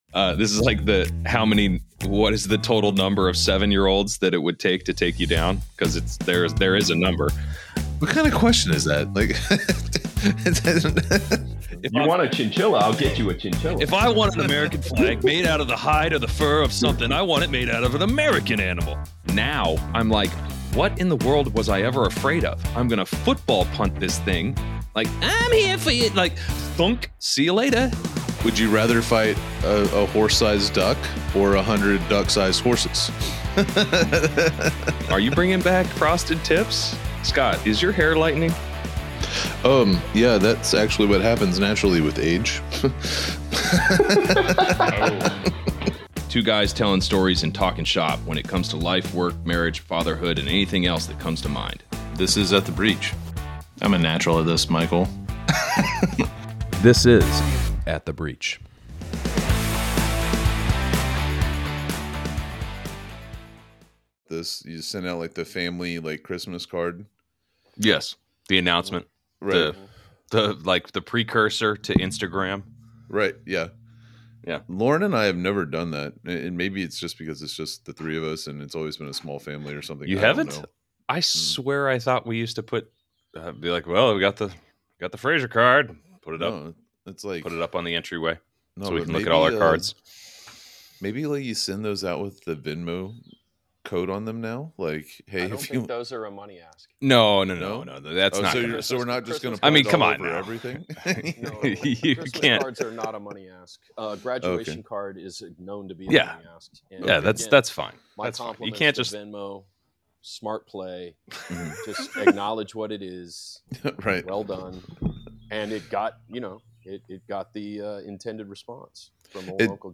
Play Rate Listened List Bookmark Get this podcast via API From The Podcast Welcome to At the Breach, a podcast hosted by two veterans who juggle the roles of fathers, husbands, and Americans.